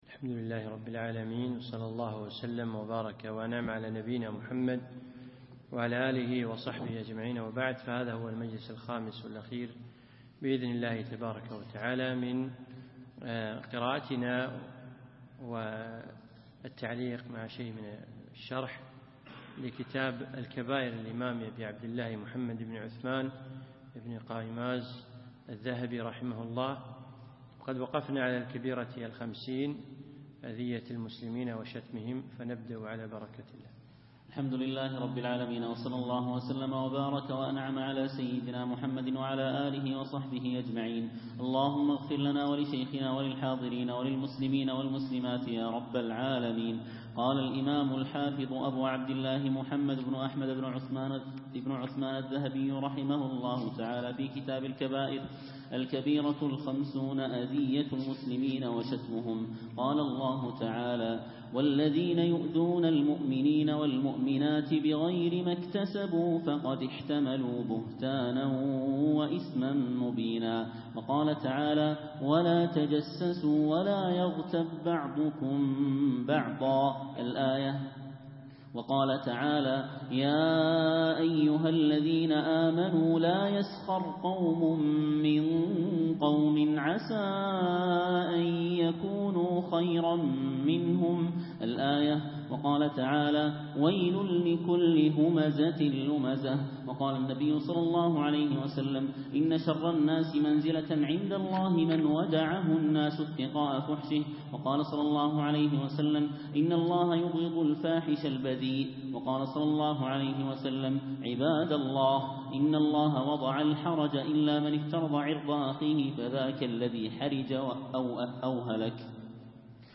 يوم الخميس 19 ذو العقدة 1436هـ 3 9 2015م في مسجد عائشة المحري المسايل